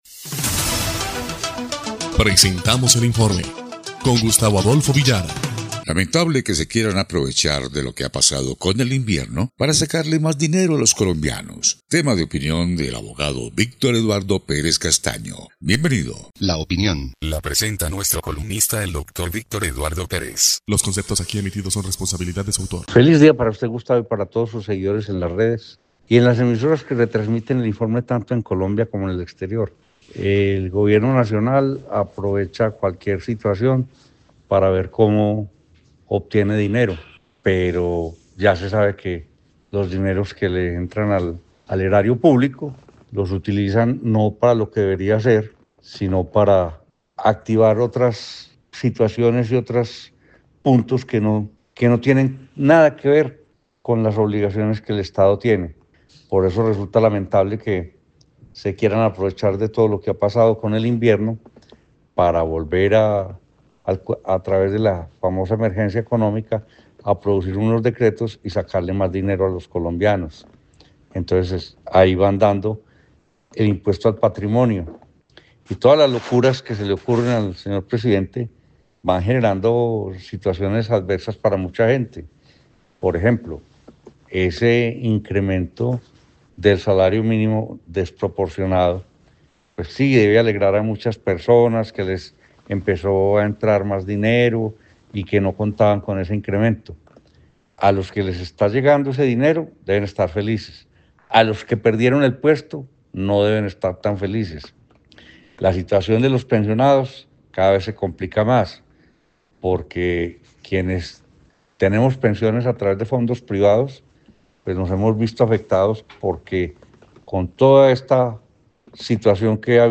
EL INFORME 2° Clip de Noticias del 25 de febrero de 2026